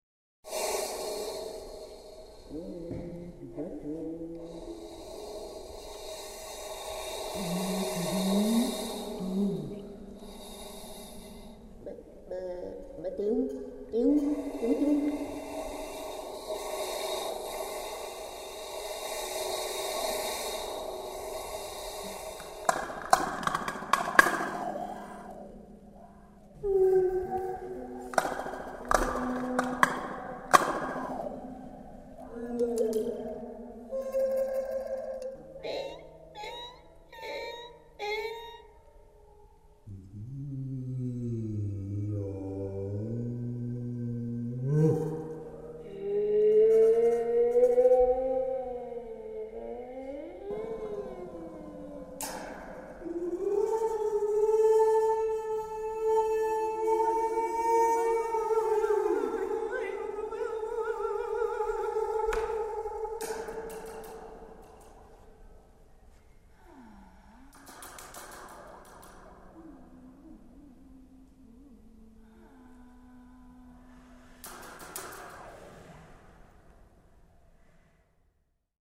Quelques impros réalisées avec le groupe.
Nicollophone
4Nicollophone.mp3